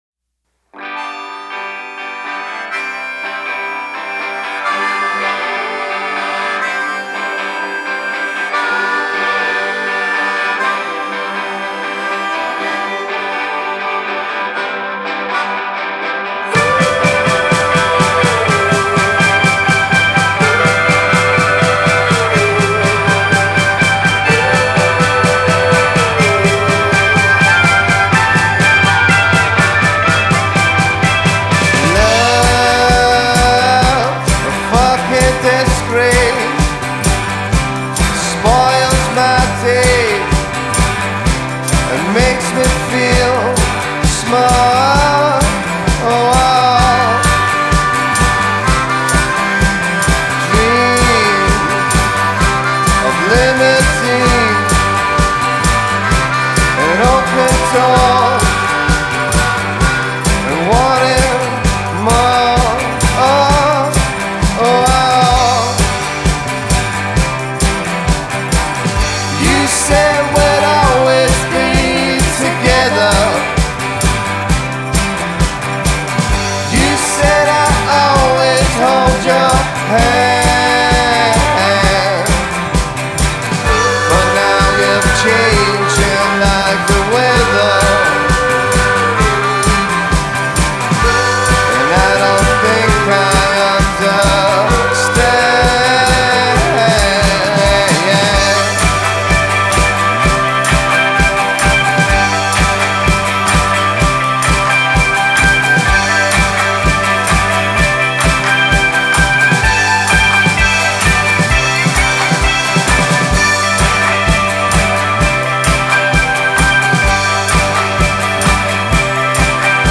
Genre: Indie/Psychedelic-Rock